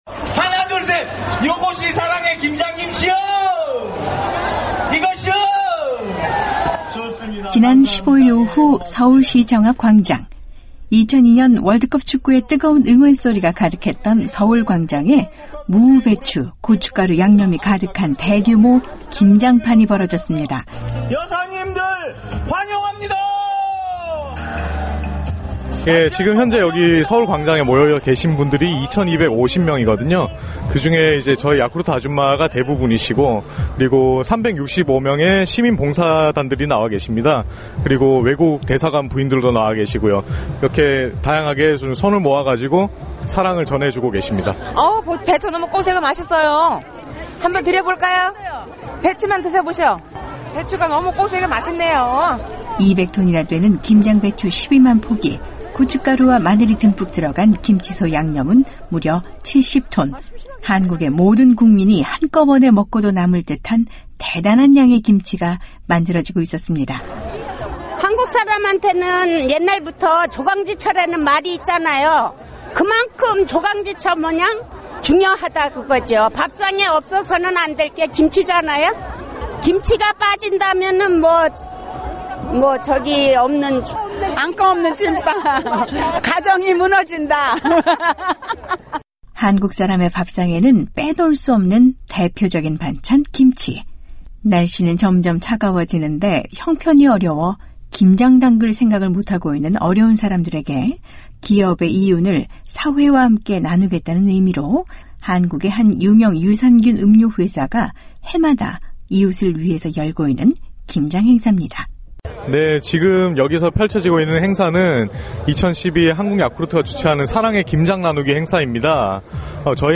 지난 15일 서울에서는 2천2백여 명의 아주머니들이 한자리에 모여 200톤 가량의 김치를 담그는 큰~ 행사가 열렸다고 합니다. 잔칫집 같았던 김장 담그는 현장으로 안내하겠습니다.